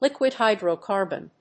liquid+hydrocarbon.mp3